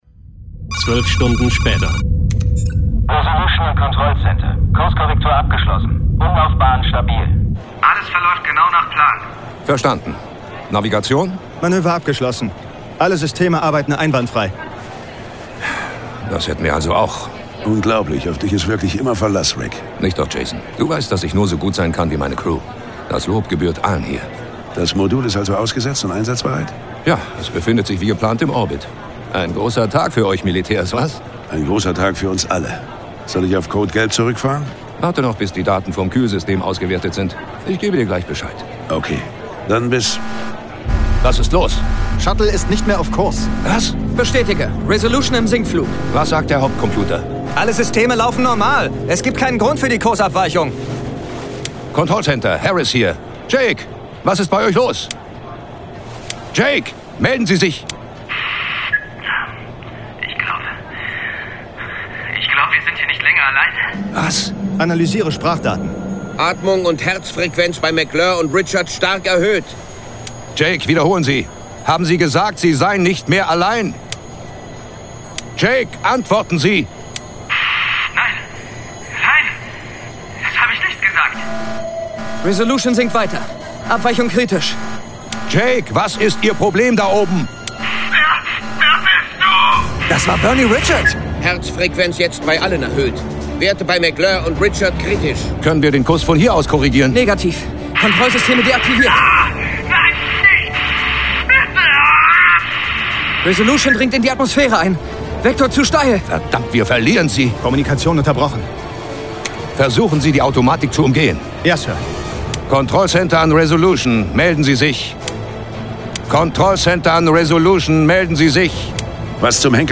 John Sinclair - Folge 53 Dämonen im Raketencamp. Hörspiel.